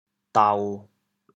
调: 低 国际音标 [tau]